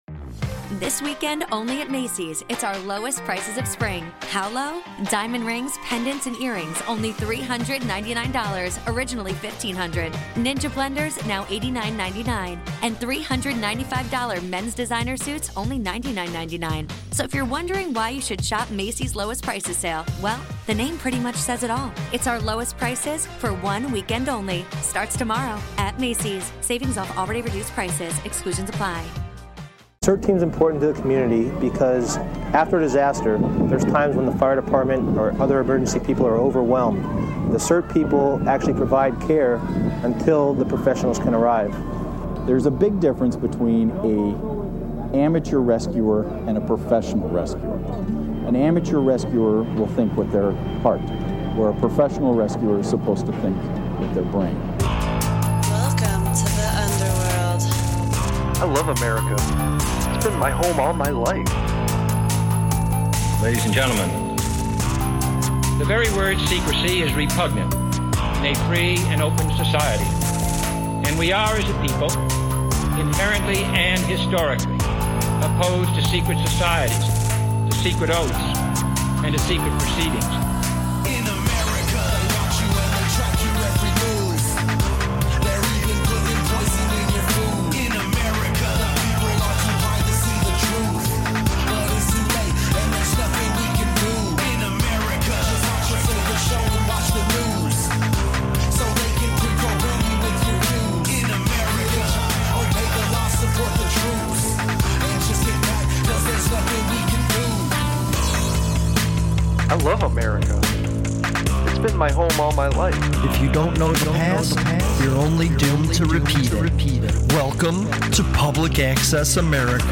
Common sense and critical thinkings meet political dissidence and systemic inequality. Real people, real conversations.